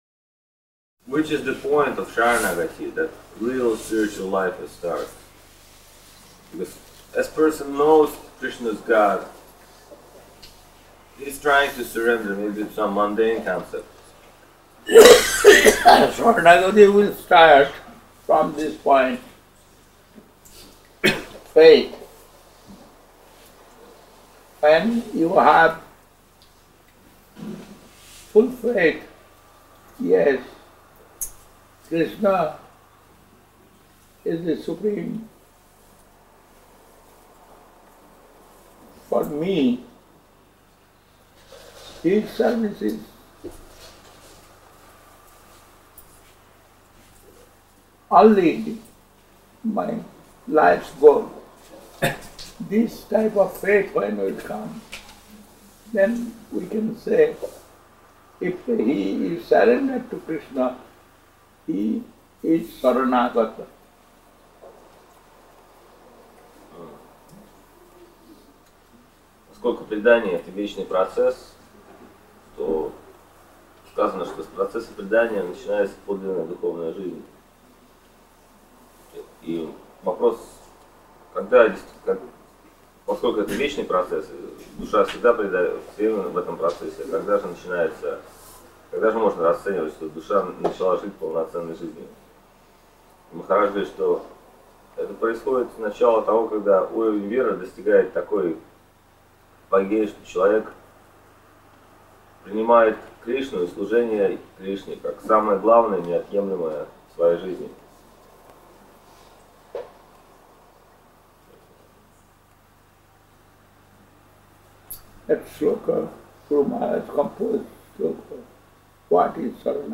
Место: Лахтинский Центр Бхакти йоги